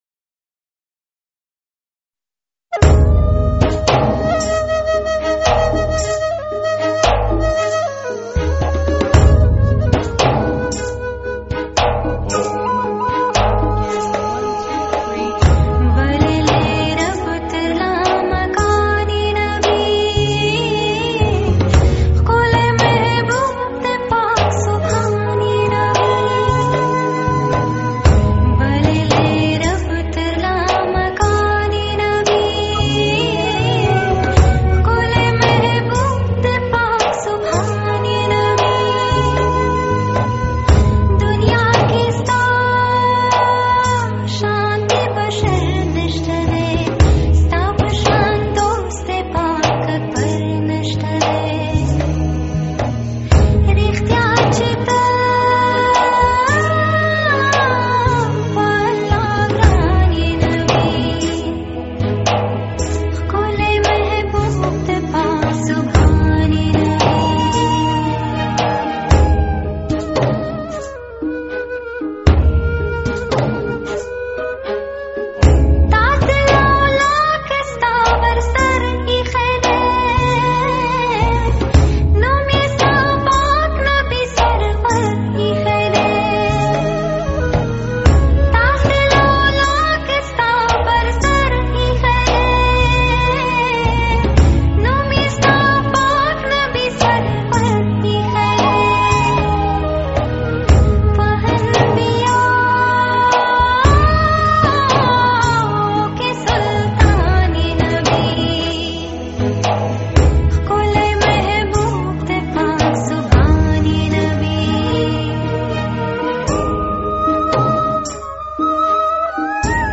نعت شریف